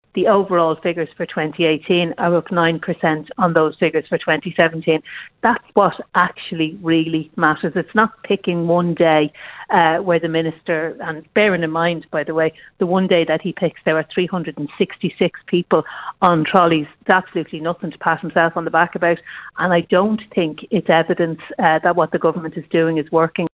Health Minister Simon Harris called the drop “significant”, but Deputy O’Reilly says those figures only mask the real picture………….